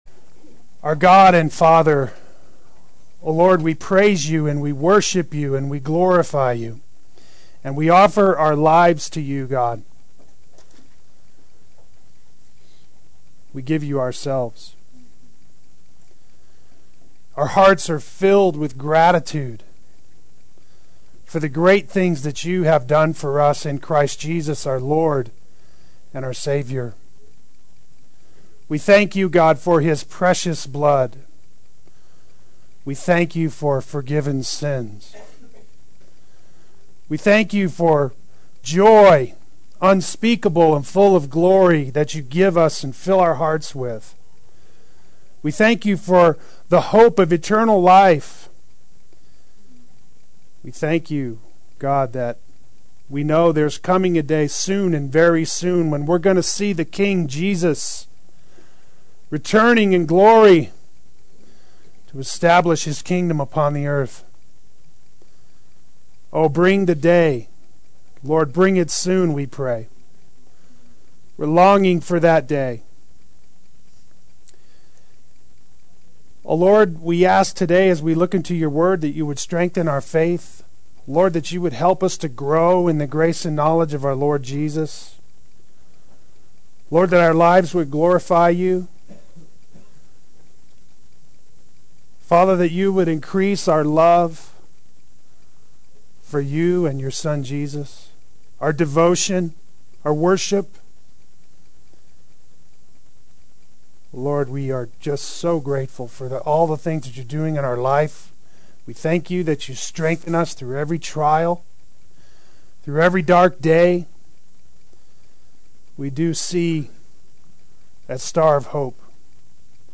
God is Manifesting His Glory Adult Sunday School